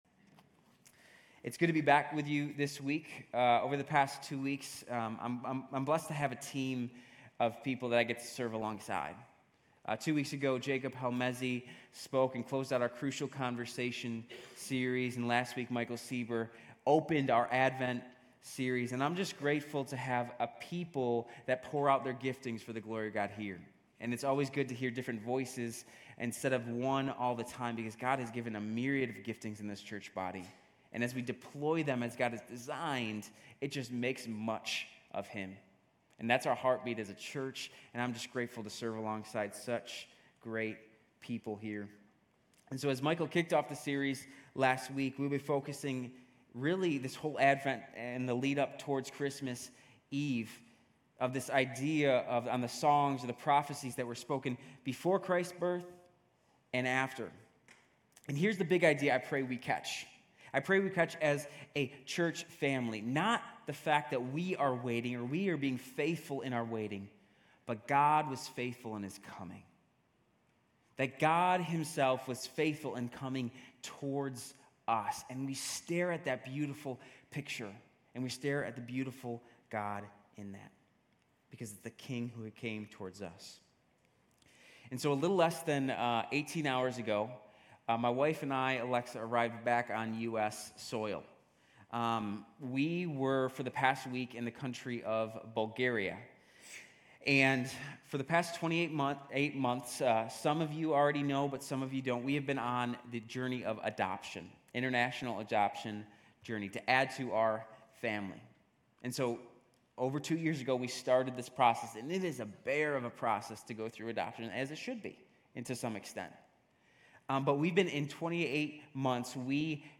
GCC-UB-December-3-Sermon.mp3